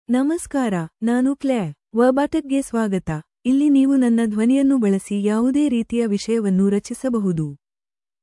ClaireFemale Kannada AI voice
Claire is a female AI voice for Kannada (India).
Voice sample
Listen to Claire's female Kannada voice.
Female